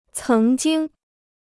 曾经 (céng jīng): once; already.